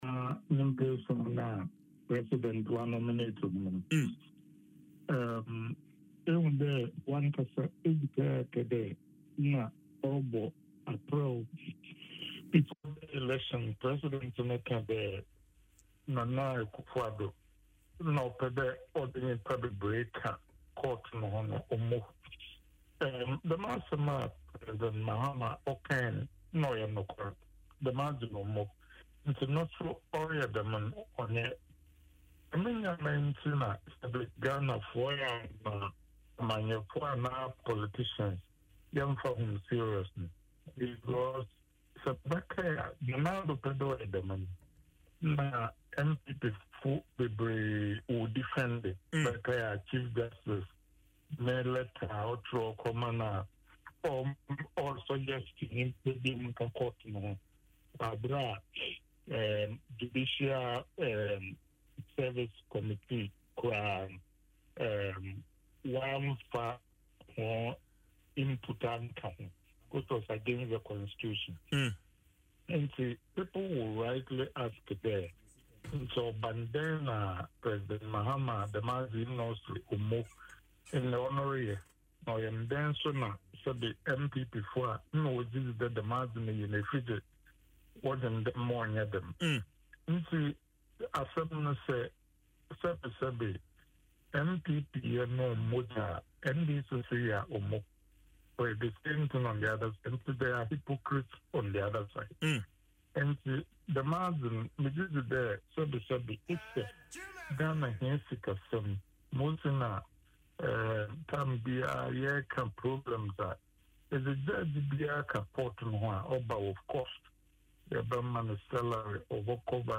In an interview on Adom FM’s Dwaso Nsem, Dr. Kennedy stressed his disagreement with Mahama’s decision, describing it as hypocritical.